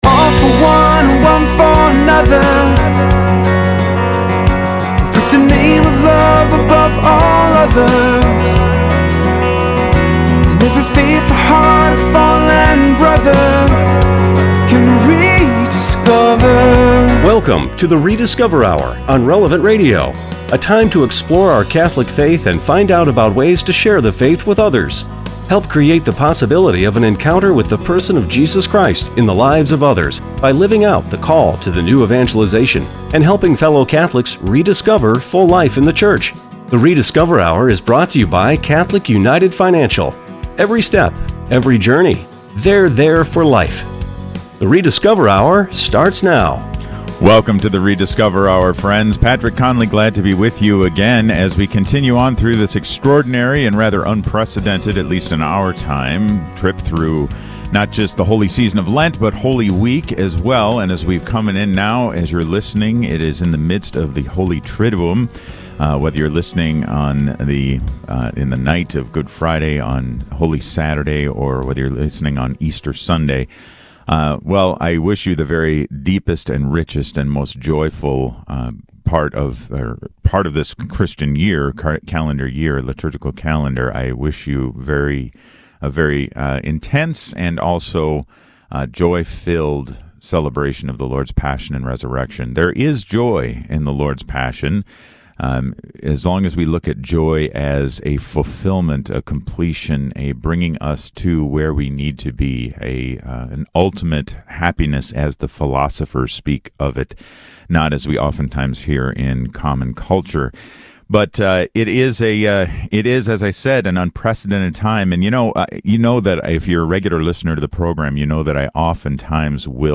Next, Archbishop Bernard Hebda discusses the Minnesota Catholic Relief Fund, an emergency relief fund created to meet the needs of our Catholic community arising from the COVID-19 pandemic.